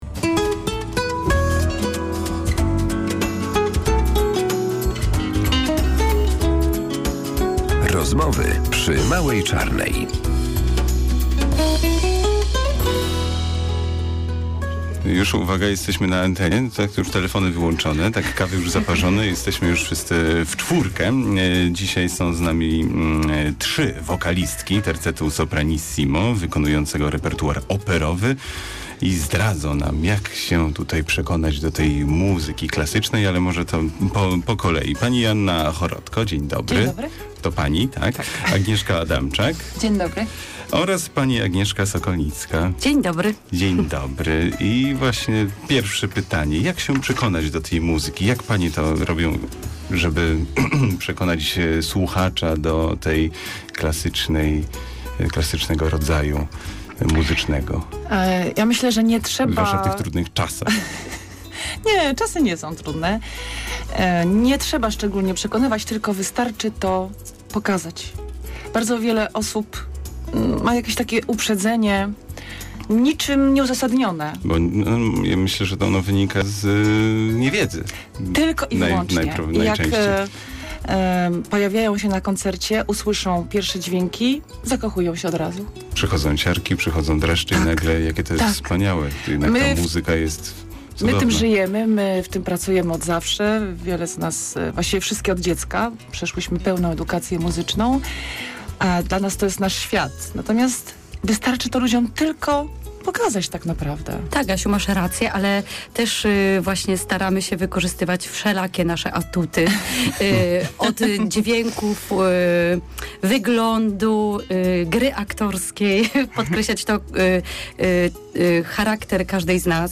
Tytuł: Mała czarna - Sopranistki